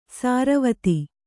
♪ sāravati